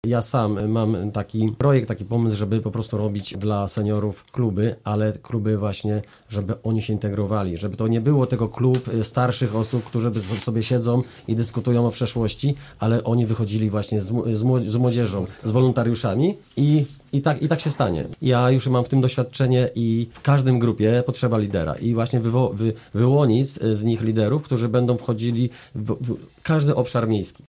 – Seniorzy są ważni dla miasta – komentował radny Tomasz Manikowski z klubu Gorzów Plus: